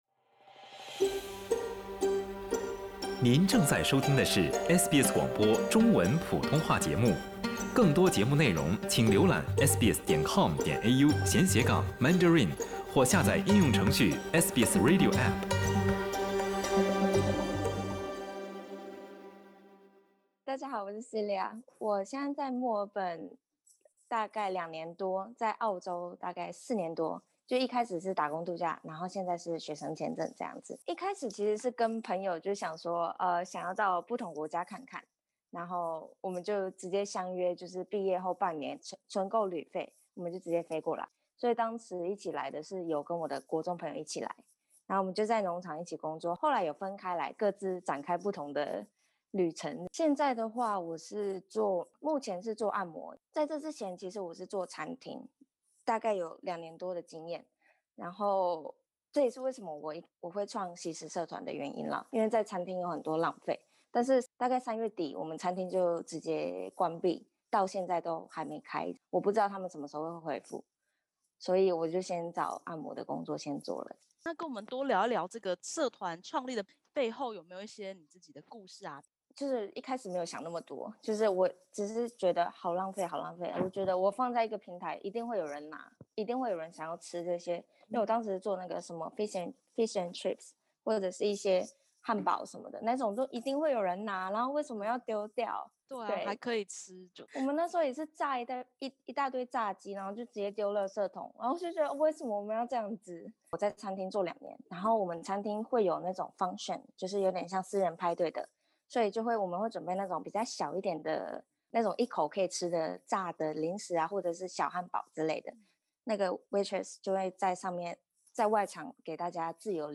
点击首图收听完整采访音频。